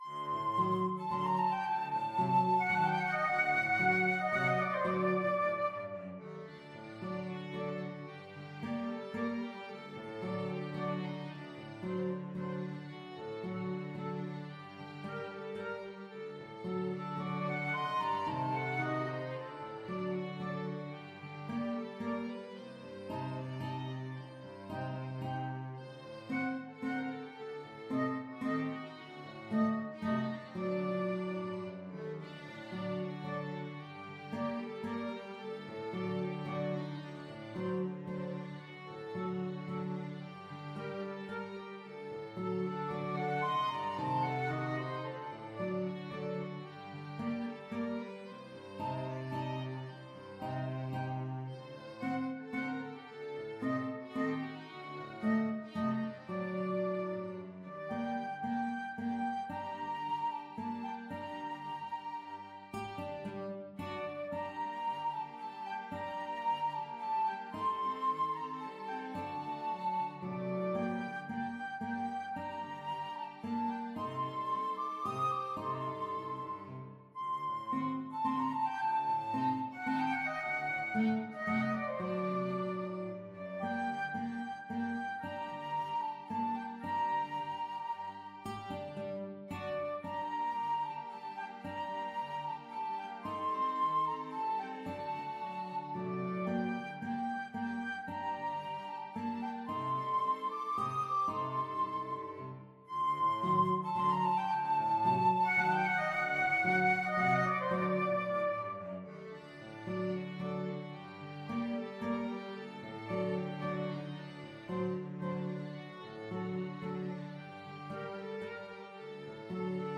ViolinAccordion Lead Sheet (Chords)
FluteTrumpet
GuitarTrombone
CelloTuba
3/4 (View more 3/4 Music)
Moderato Waltz Tempo ( = c. 112)
Israeli